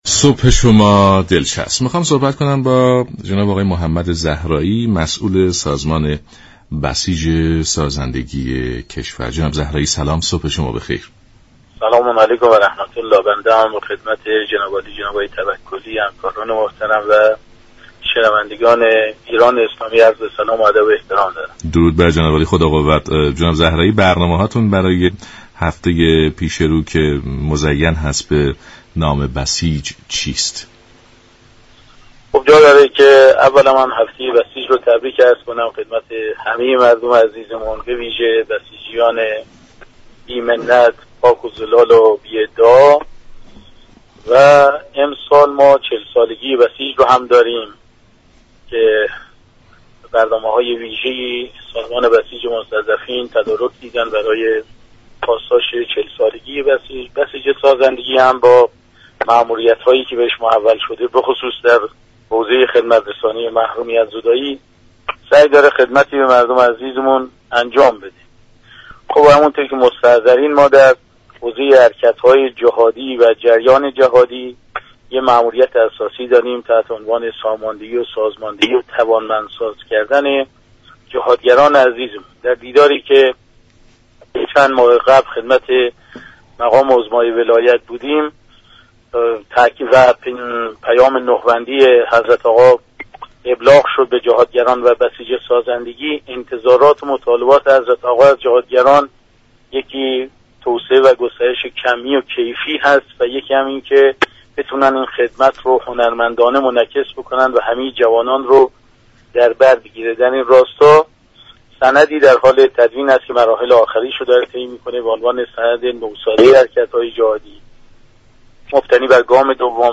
به گزارش شبكه رادیویی ایران، «محمد زهرایی» مسئول سازمان بسیج سازندگی كشور در گفت و گو با برنامه «سلام صبح بخیر» به هفته بسیج و ماموریت های این سازمان در حوزه حركت های جهادی اشاره كرد و گفت: ساماندهی، سازماندهی و توانمندسازی جهادگران كشور یكی از برنامه های سازمان بسیج سازندگی كشور است.